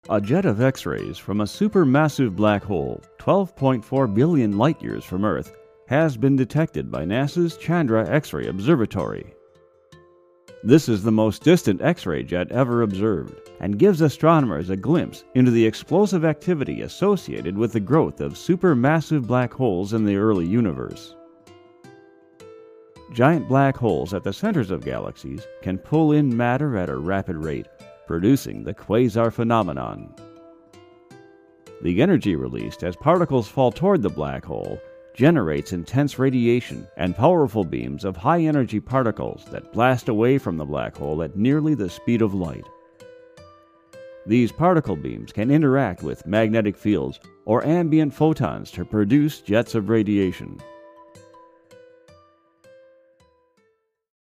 U.S. English ,Male, Middle Age, Other qualities include Authoritative, Believable, Confident, Conversational, Corporate, Educated, Genuine,Informative, Intelligent, Mature, Narrator
middle west
Sprechprobe: eLearning (Muttersprache):